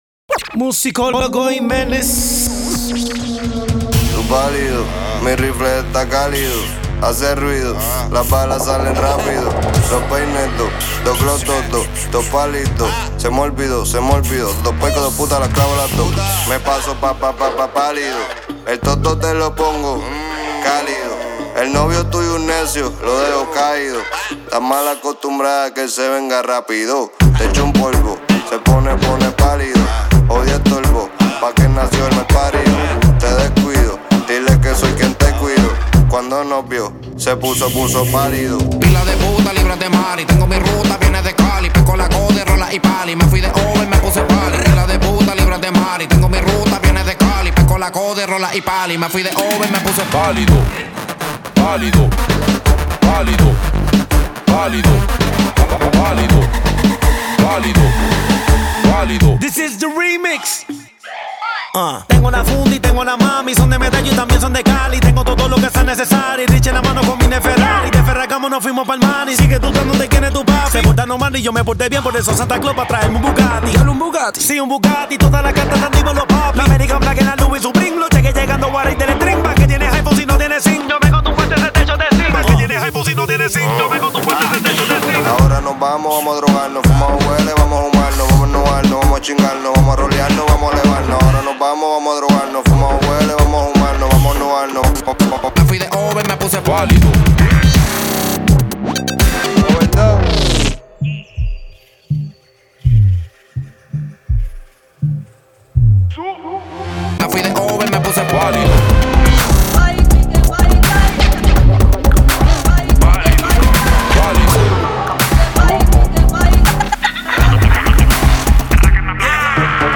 Genre: Punjabi